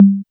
CONGA808-1 1.wav